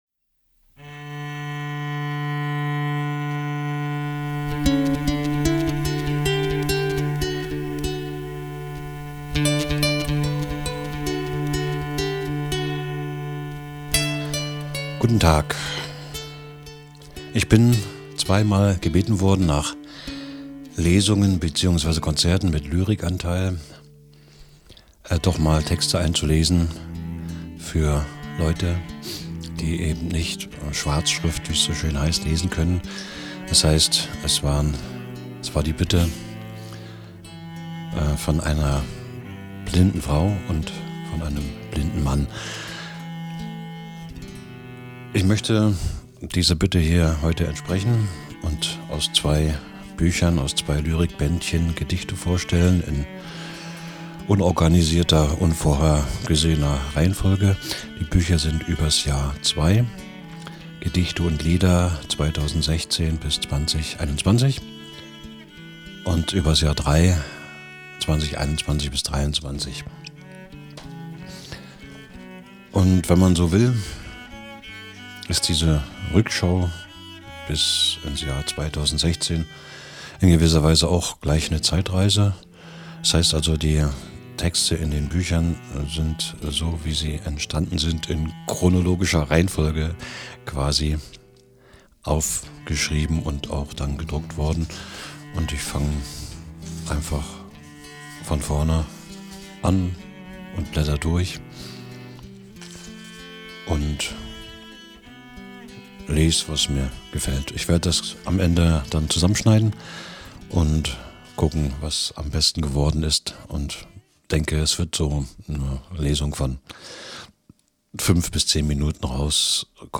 lesung teil 1
lesung-teil-1.mp3